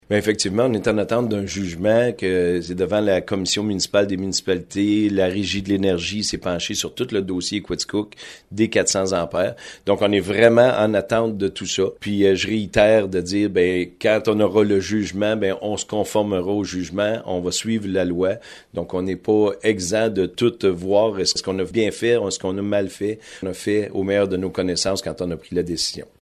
De passage dans les studios de CIGN FM, ce matin (mardi), le maire de Coaticook, Simon Madore, a exprimé son désir de clore le sempiternelle dossier des compteurs électriques en 2026.